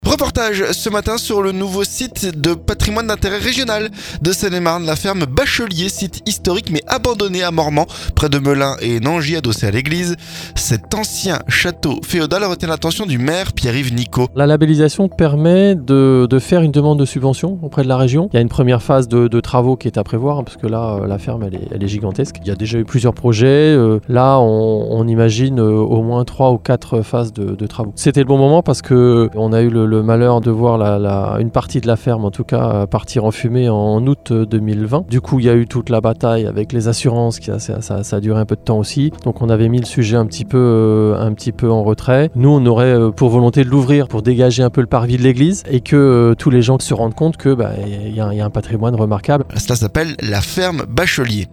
Reportage ce mardi sur le nouveau site patrimoine d'intérêt régional de Seine-et-Marne.